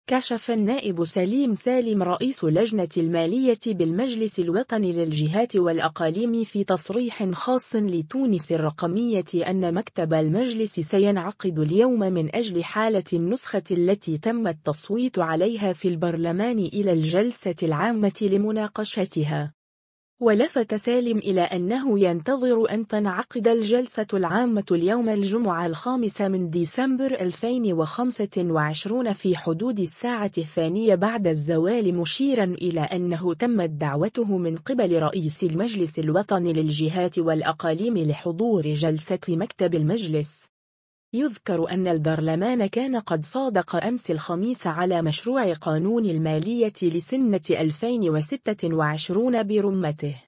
كشف النائب سليم سالم رئيس لجنة المالية بالمجلس الوطني للجهات والأقاليم في تصريح خاص لـ”تونس الرقمية” أن مكتب المجلس سينعقد اليوم من أجل احالة النسخة التي تم التصويت عليها في البرلمان الى الجلسة العامة لمناقشتها.